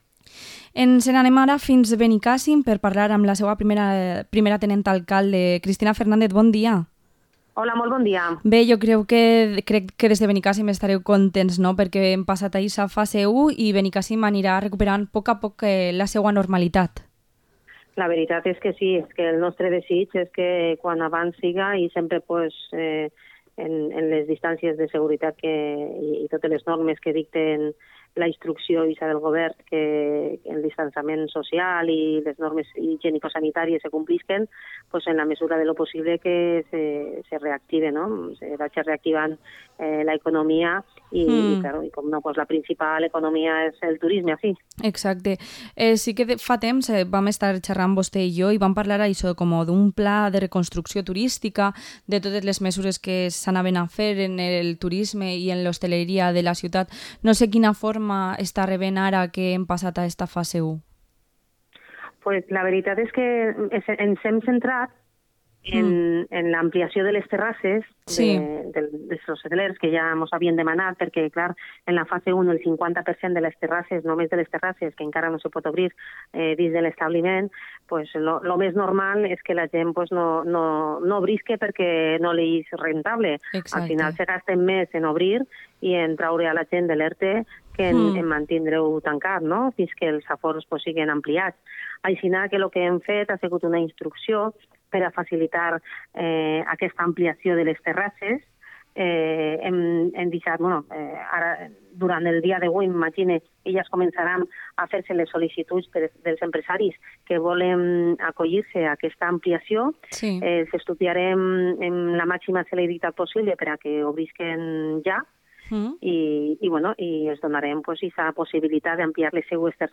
Entrevista a Cristina Fernández, primera teniente alcaldesa de Benicassim